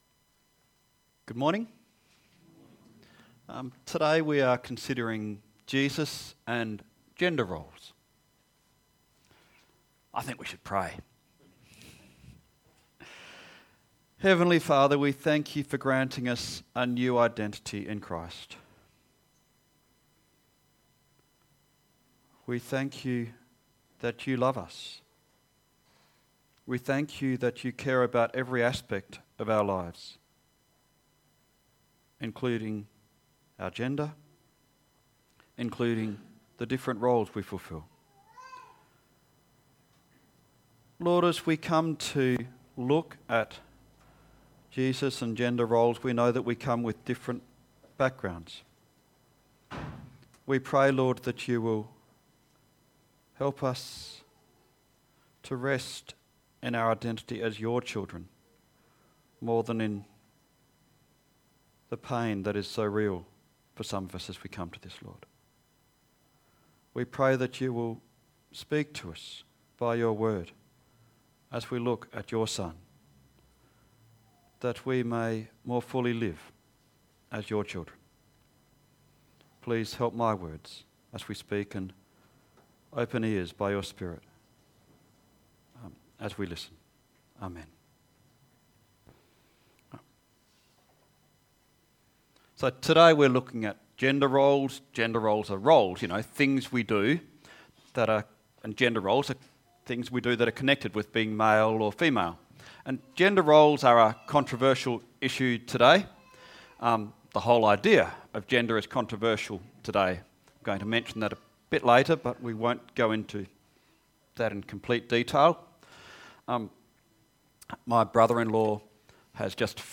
Gender Roles Service Type: Sunday AM Preacher